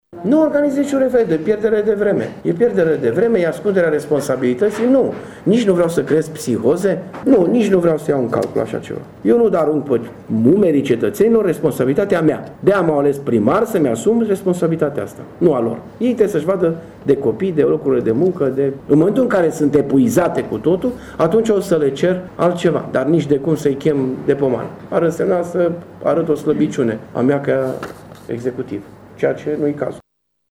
Primarul Dorin Florea spune că un astfel de referendum nu este necesar şi că nu doreşte să arunce o astfel de responsabilitate pe umerii populaţiei: